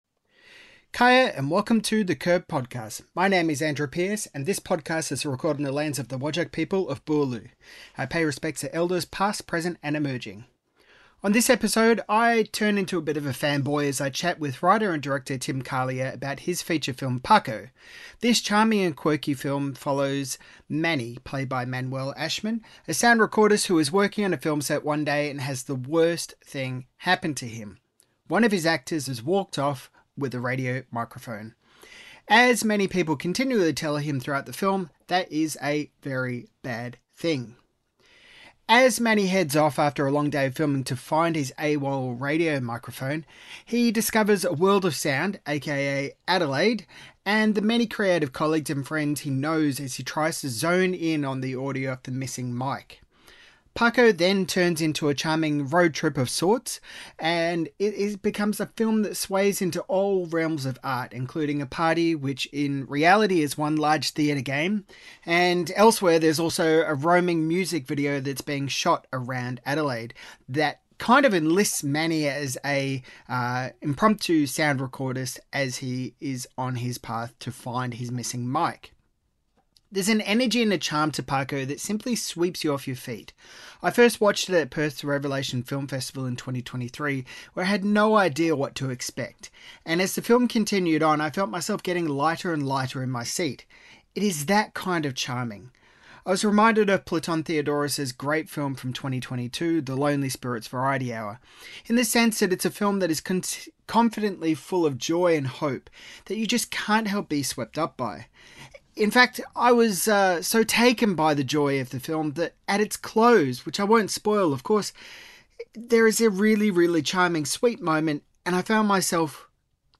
Interview - The Curb